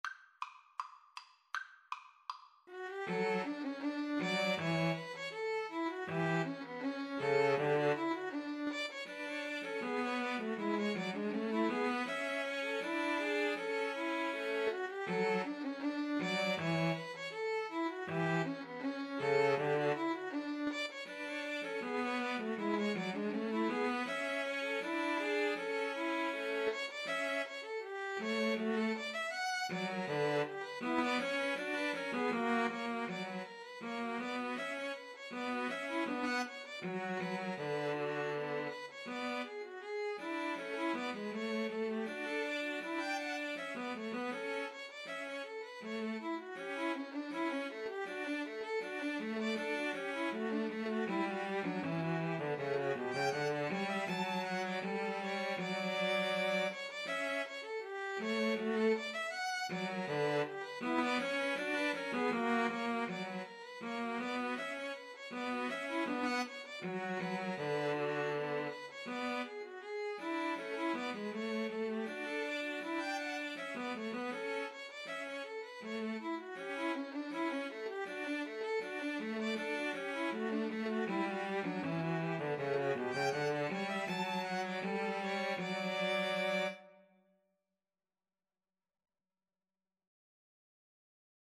Play (or use space bar on your keyboard) Pause Music Playalong - Player 1 Accompaniment Playalong - Player 3 Accompaniment reset tempo print settings full screen
D major (Sounding Pitch) (View more D major Music for String trio )
Classical (View more Classical String trio Music)